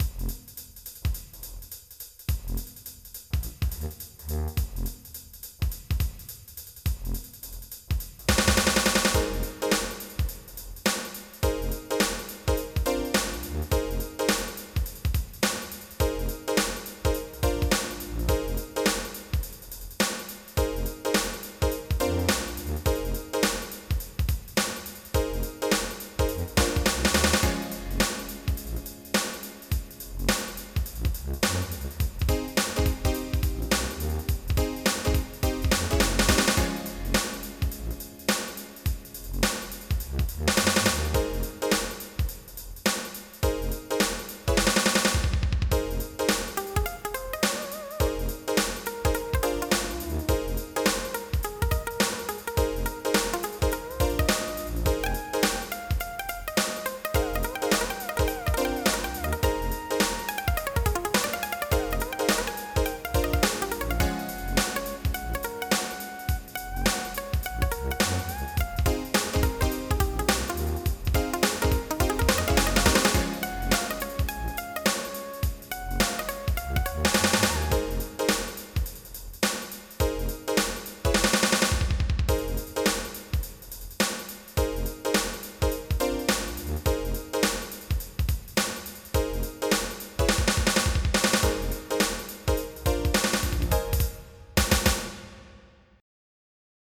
MIDI Music File
Type General MIDI
techno1.mp3